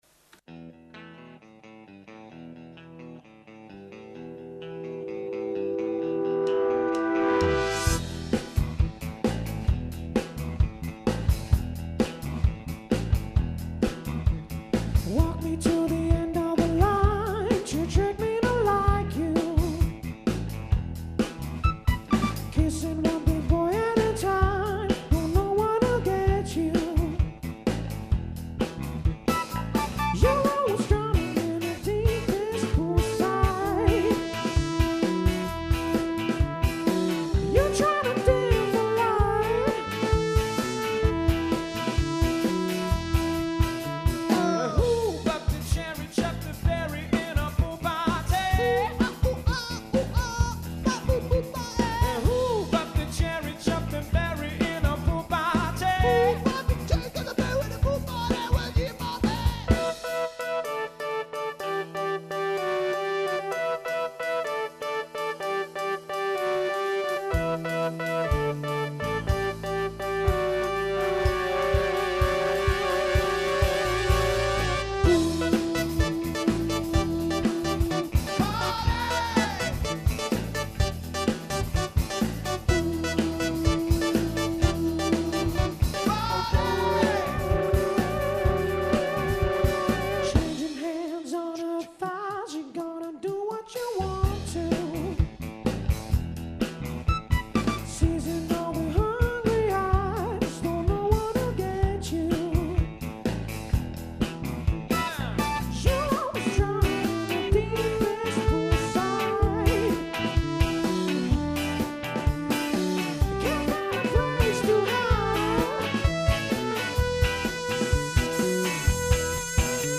Lebanese rock quartet
guitar & vocals
synths
drums
for an interview and three exclusive live performances